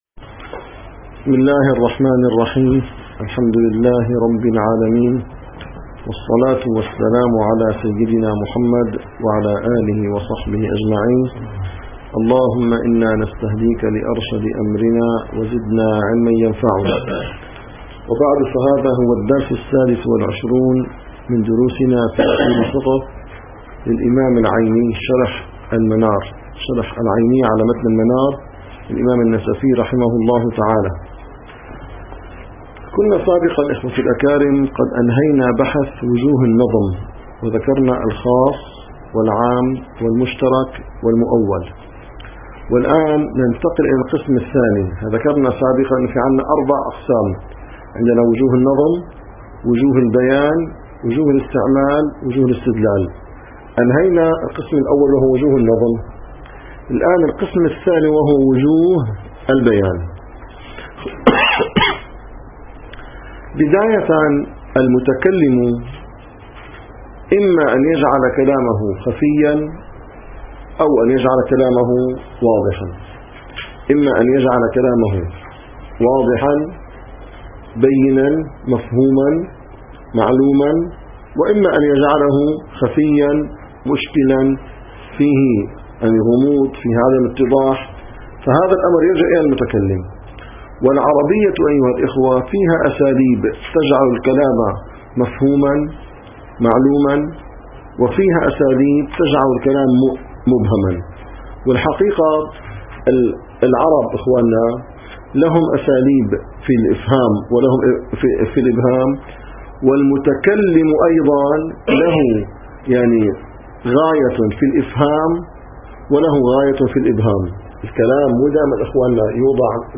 - الدروس العلمية - الحنفي - شرح العيني على أصول المنار - 25- أقسام واضح الدلالة